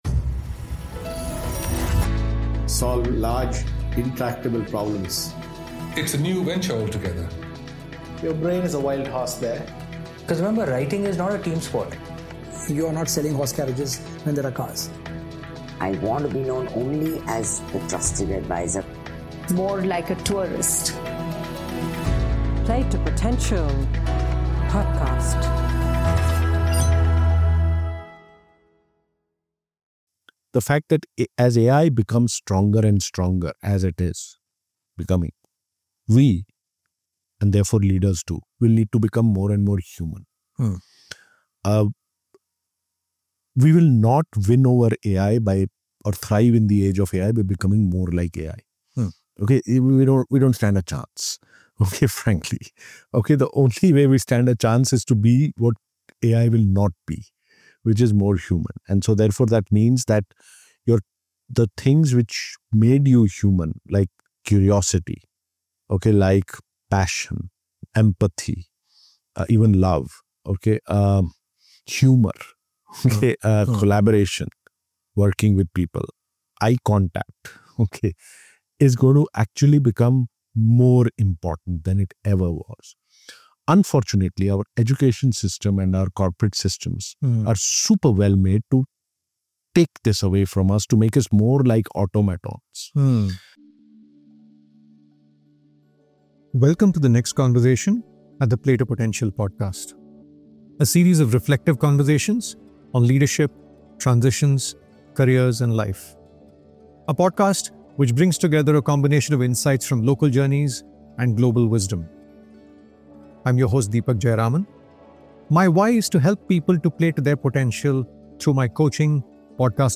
In this conversation, we explore his journey from a corporate career to entrepreneurship and his work in helping individuals and organizations build AI literacy.